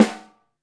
METL HARM.wav